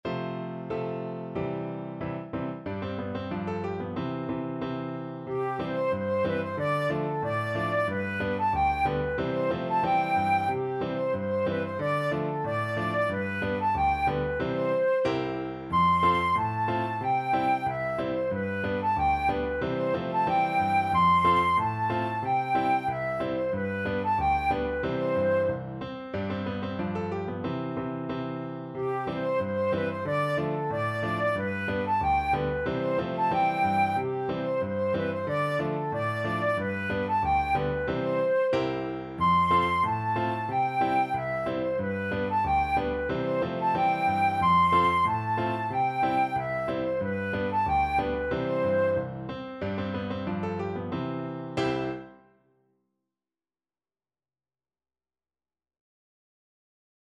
Flute version
Jolly =c.92
2/2 (View more 2/2 Music)
Flute  (View more Easy Flute Music)
Swiss